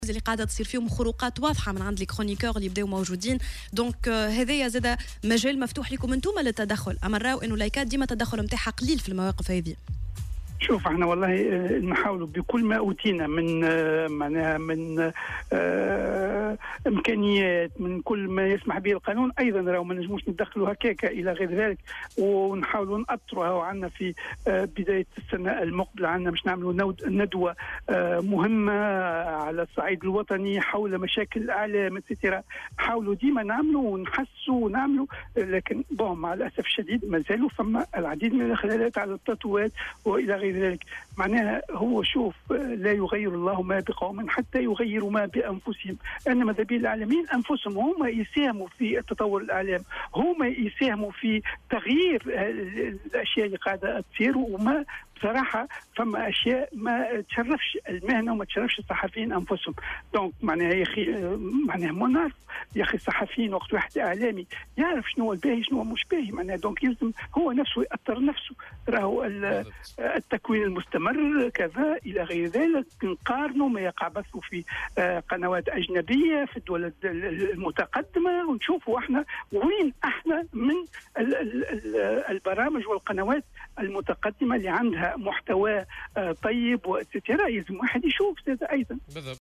وأوضح في تصريح اليوم لـ"الجوهرة أف أم" أن "الهايكا" تحاول القيام بعمليات تأطير للصحفيين والإعلاميين، مشيرا إلى أنه سيتم خلال السنة القادمة تنظيم ندوة على الصعيد الوطني حول المشاكل التي تواجه قطاع الإعلام.